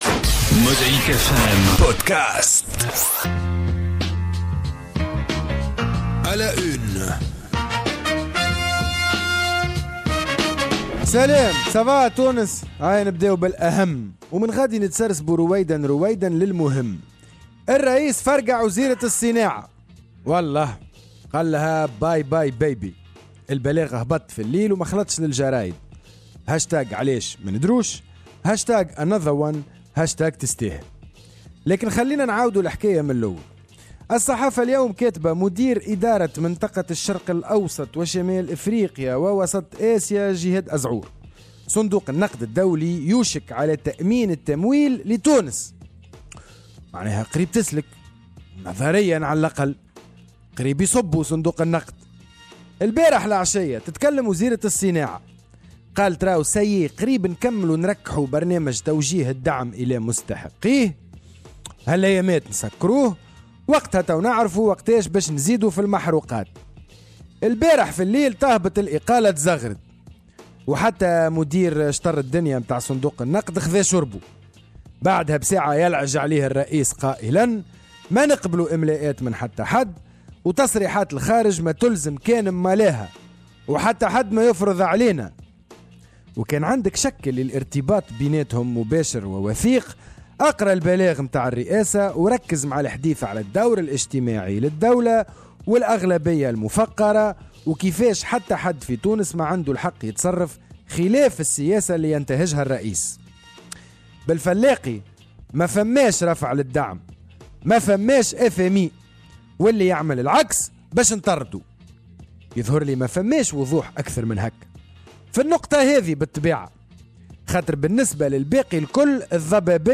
قراءة ساخرة في أبرز العناوين الصحفية اليومية والأسبوعية